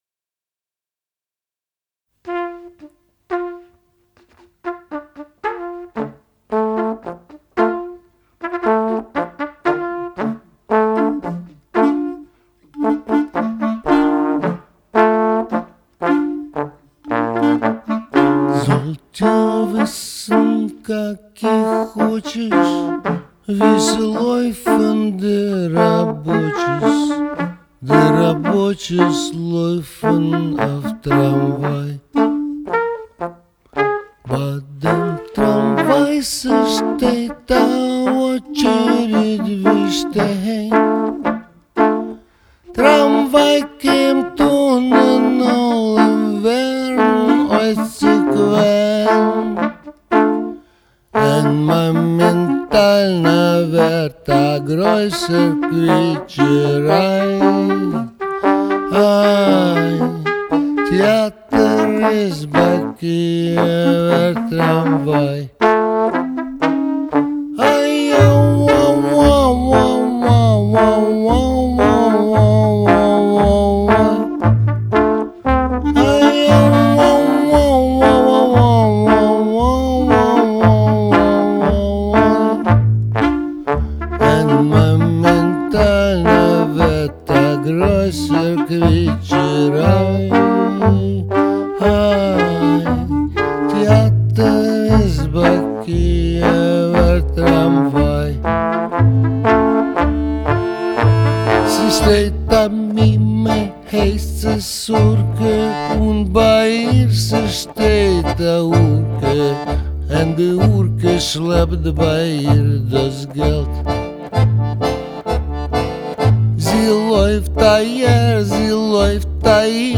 Genre: Klezmer, Folk, World